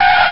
squeel1.ogg